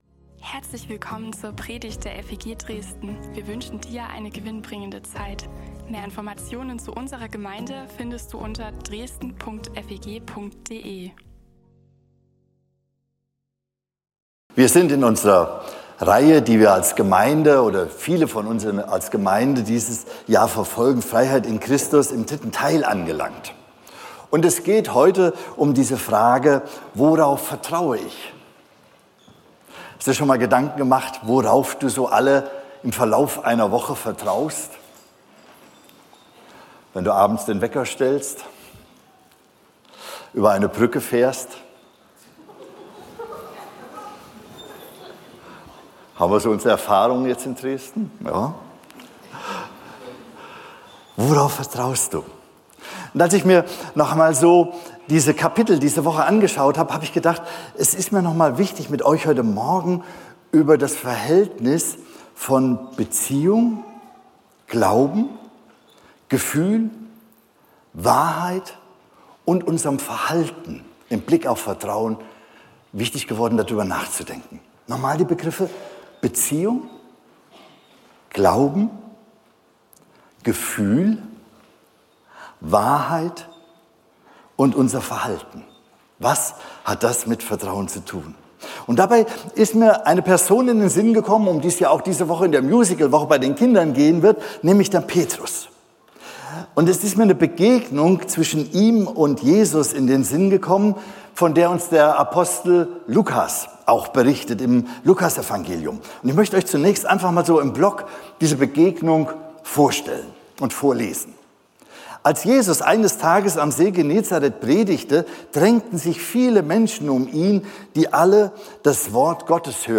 Diese Predigt ist die dritte dazugehörige Predigt.